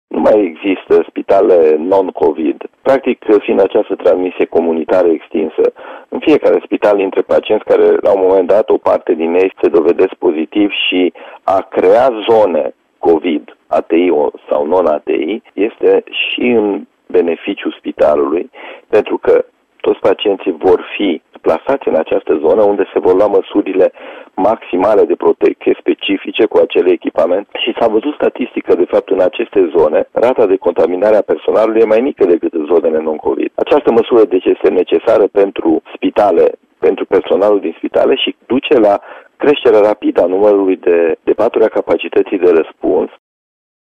Casa Austria, clinică a Spitalului Județean Timișoara, va prelua bolnavi de COVID-19 în perioada imediat următoare. Anunțul a fost făcut în această dimineață, la Radio Timișoara